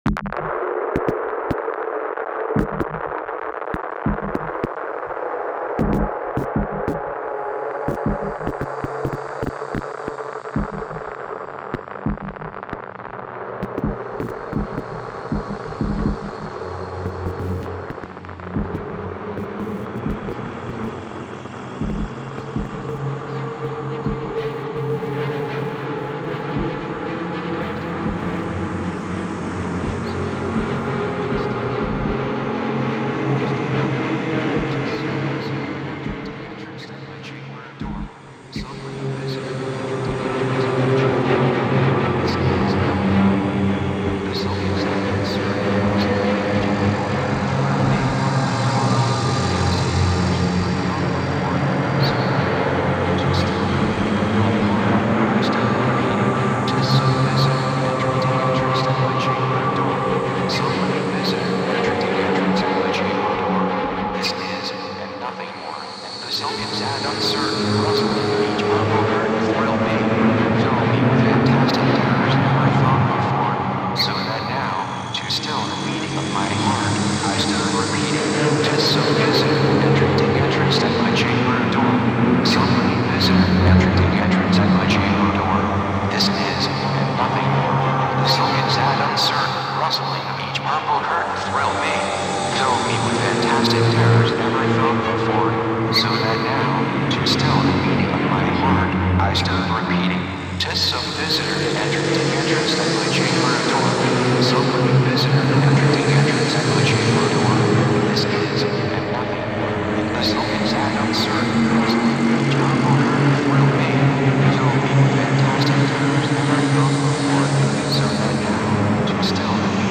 本作は大編成のストリングスと男性合唱からなるサイバー・ゴシックなオーケストレーションと、
デジタル・ハードコアなビートとのアマルガムである。
ヒトに非ざる非実存ナレーターは、MacOS X Leopardによる合成言語。
漆黒のサイバー・ハードコア・ゴシックが鳴る。